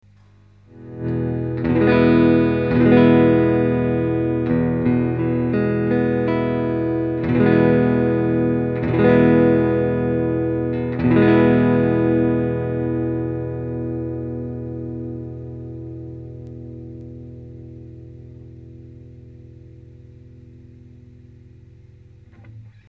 ■アンプ：Fender　Pro-Junior（15W)
■マイク：Seide　PC-VT3000/SHURE　SM57-LCE